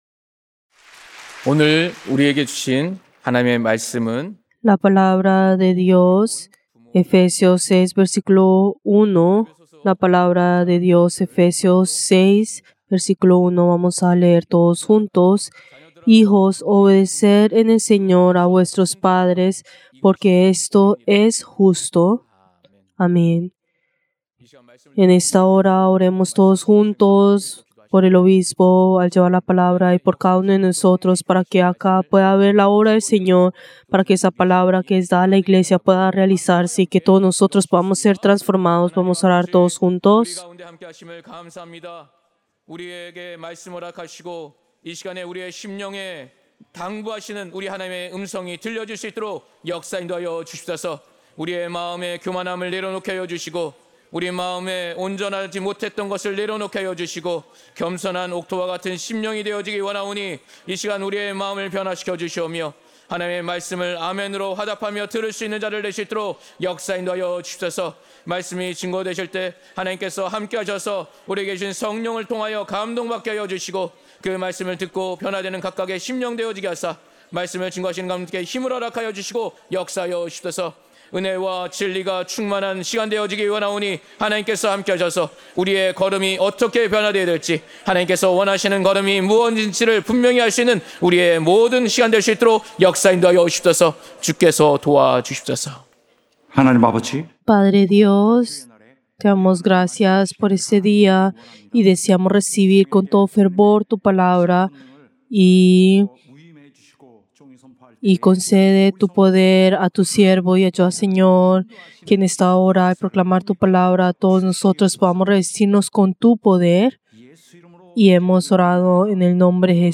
Servicio del Día del Señor del 13 de abril del 2025